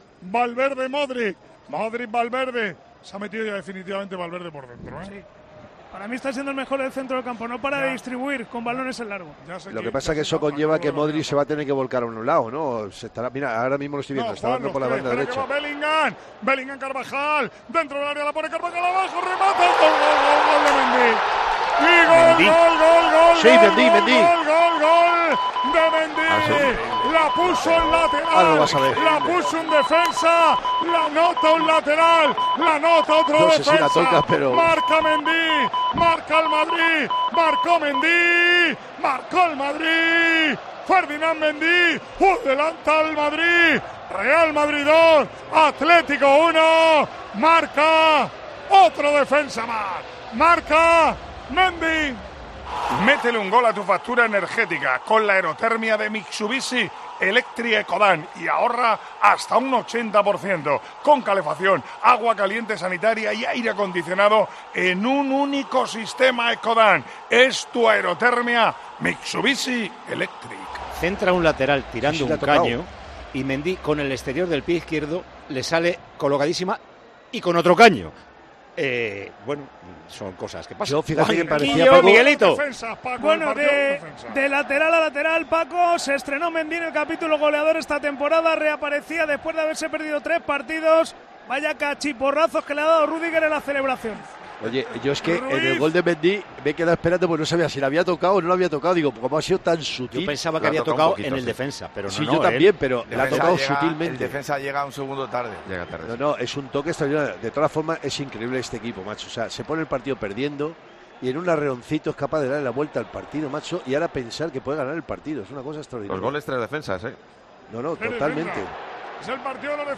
El equipo de COPE, en el Estadio Al-Awwal Park de Arabia Saudí
Así vivimos en Tiempo de Juego la retransmisión del Real Madrid - Atlético de Madrid